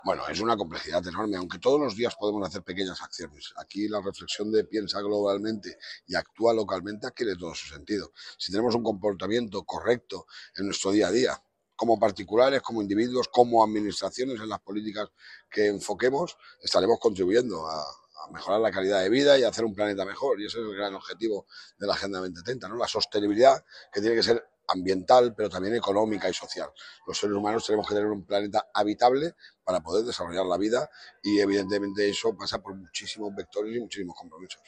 El presidente de la Diputación de Alicante participa en Málaga en la inauguración de la IV Asamblea de la Red de Entidades Locales Agenda 2030
Corte-Toni-Perez-Agenda-2030-Malaga-ok.mp3